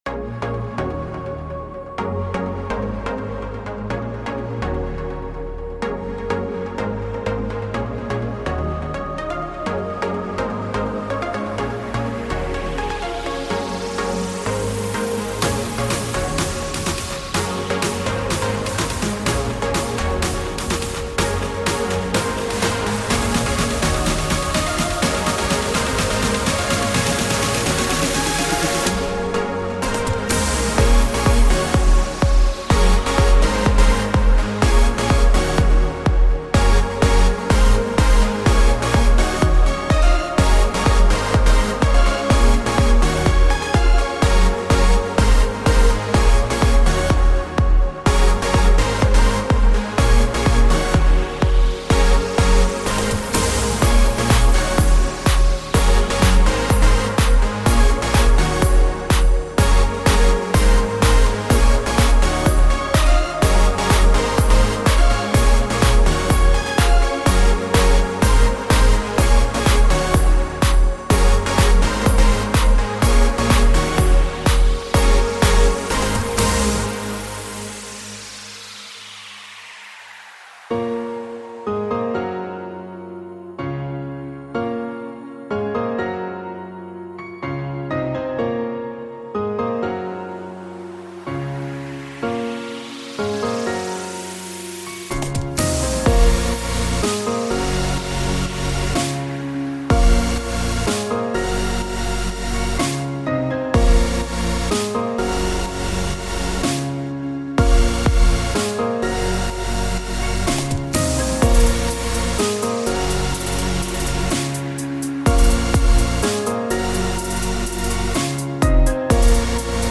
High quality Sri Lankan remix MP3 (6.2).